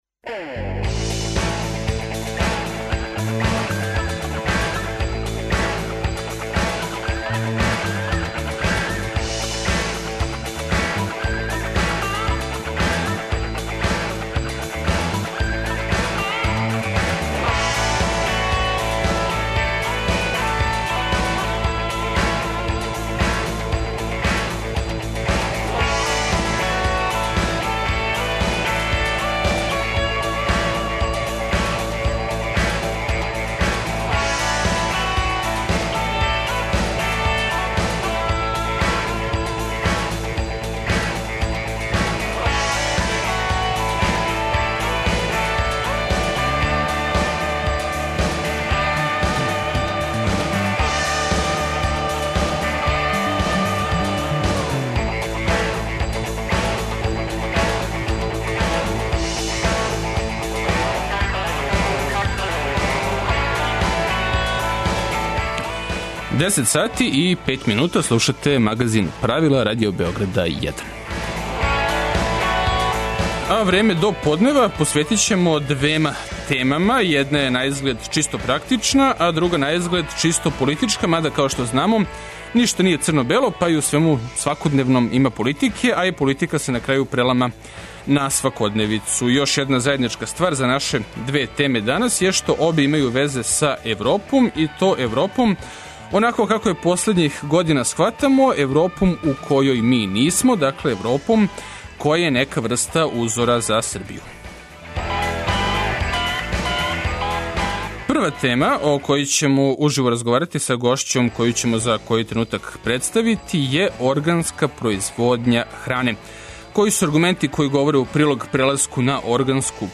Чућемо енглеског политичара који објашњава зашто Србија неће ући у Европску Унију док не разреши све проблеме са Косовом, те да је за Србију реално да добије неку врсту прикљученог чланства. Чућемо и како су за истим столом на те идеје реаговали домаћи стручњаци за проширење.